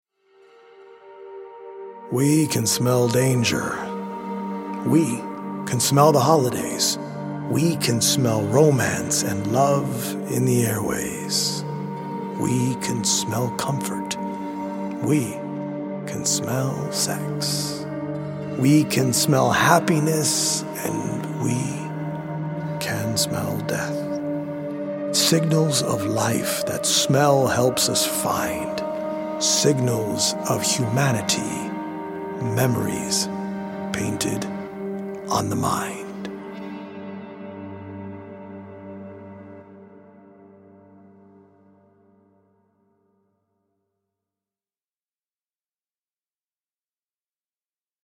poetic recordings
healing Solfeggio frequency music